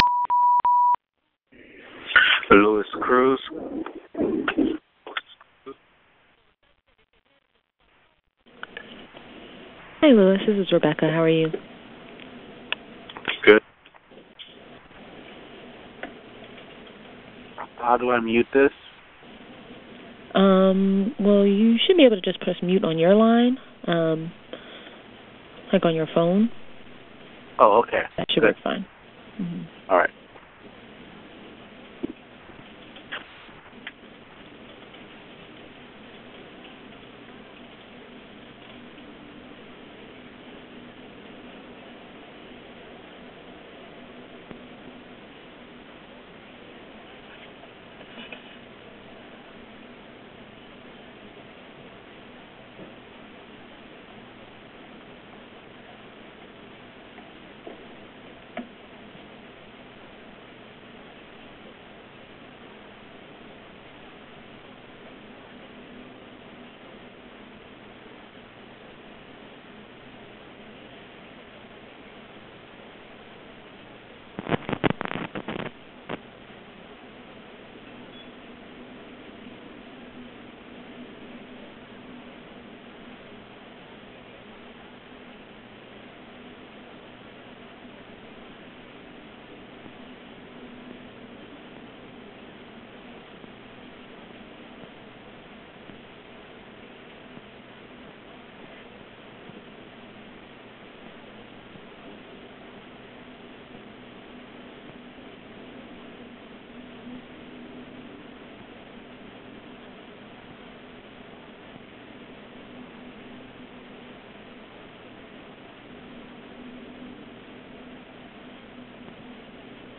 Brown Bag Lunches > Webinars